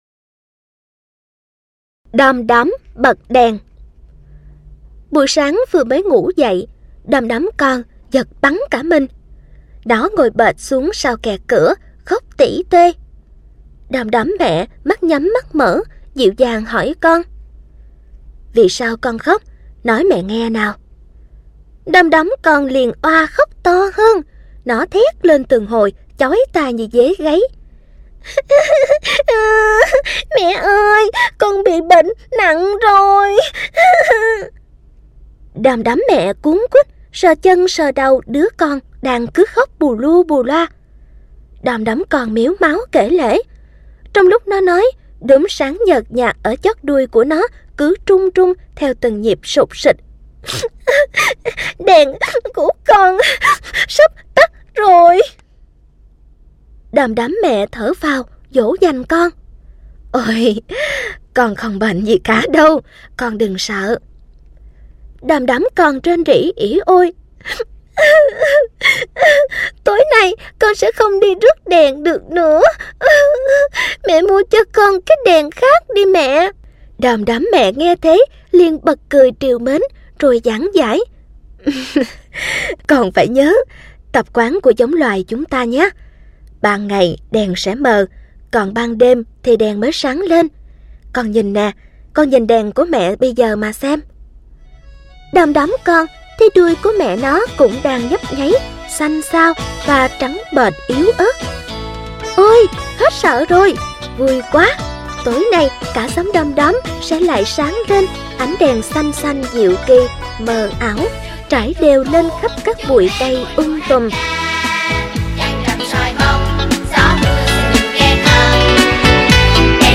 Sách nói | Xóm Đồ Chơi P32